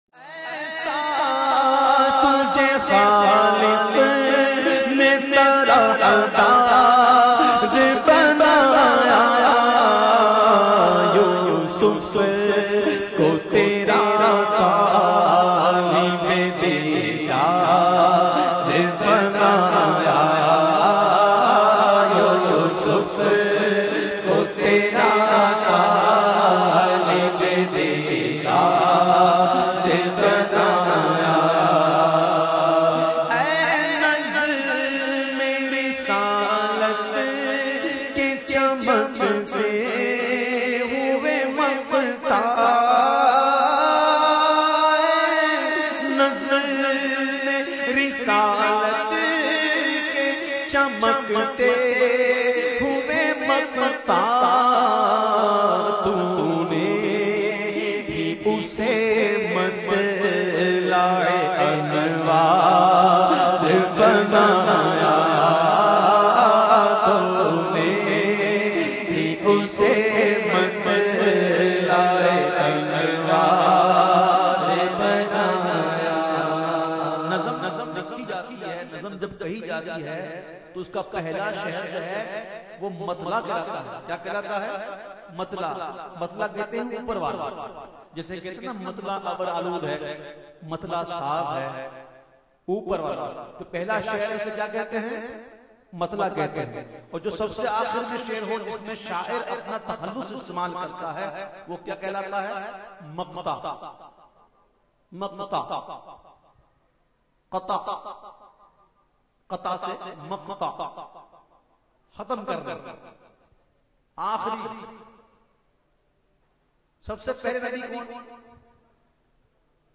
نعت